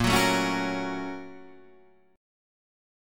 A#mM7#5 Chord